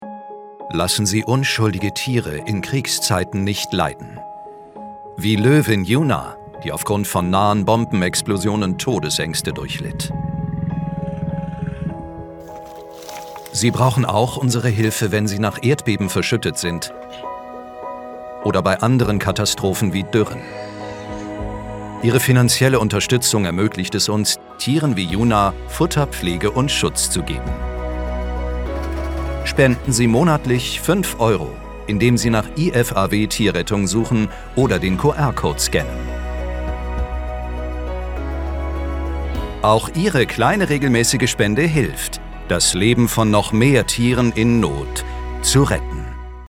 Werbung und Industriefilme
TV-Spot "Tierrettung"